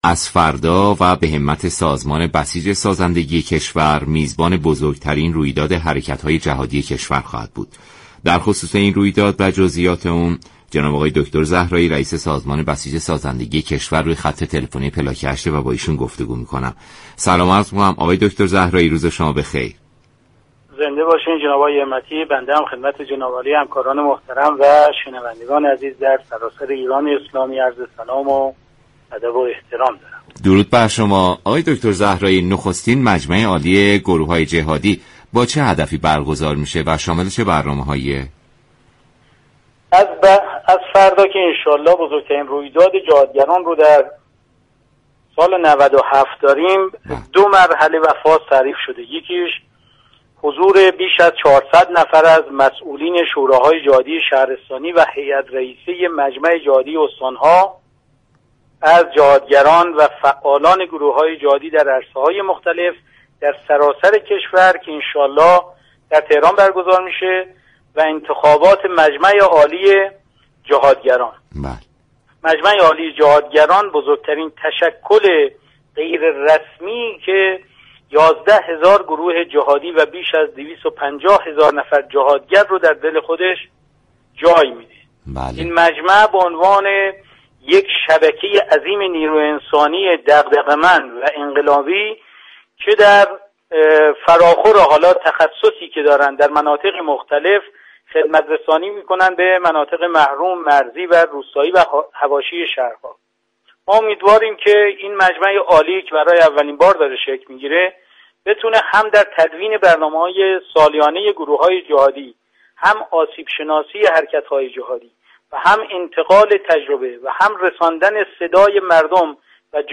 دكتر زهرایی رئیس سازمان بسیج سازندگی كشور در برنامه پلاك هشت رادیو ایران گفت : این مجمع به عنوان یك شبكه عظیم نیروی انسانی انقلابی به فراخور تخصص خدمت رسانی می كند